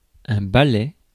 Prononciation
Synonymes caquetoir Prononciation France: IPA: /ba.lɛ/ Le mot recherché trouvé avec ces langues de source: français Traduction Substantifs 1.